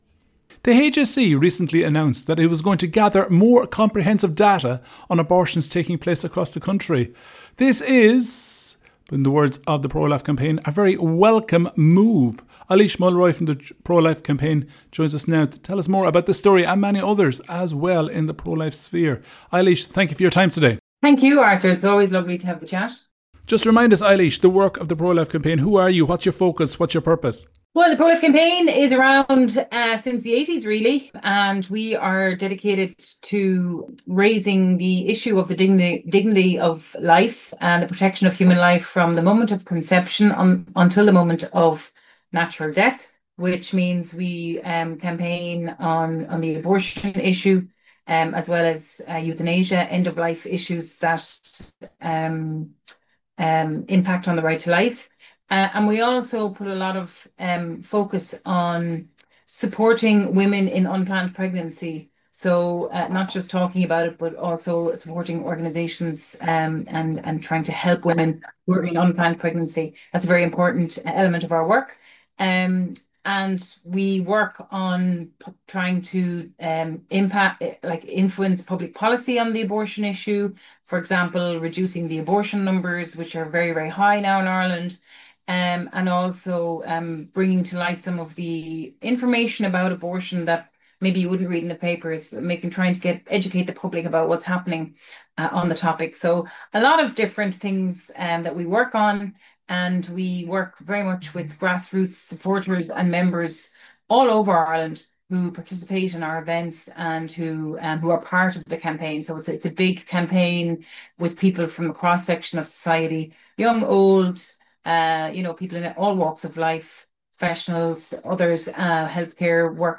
speaking on Life FM